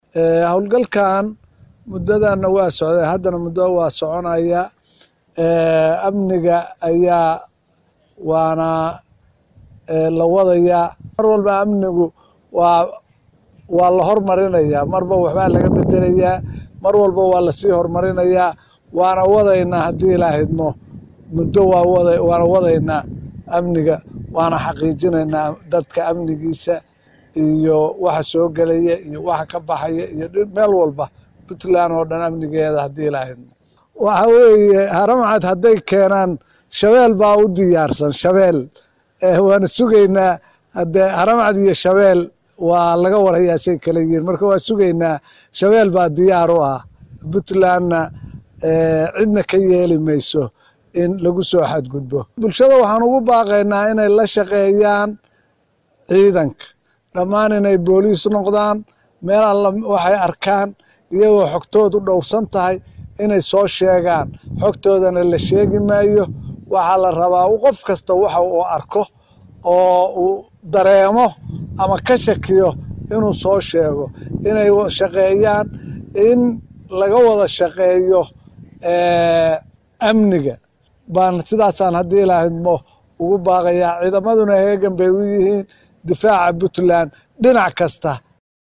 Taliyaha Ciidanka Booliska Maamulka Puntland Jeneraal Muxiyaddiin Axmed Muuse ayaa ka hadlay hadal heynta la xiriirta Ciidamada dowladda Federaalka ah ee ay geyneyso Magaalada Gaalkacyo.
Cod-Jeneraal-Muxudiin-Axmed-Muuse.mp3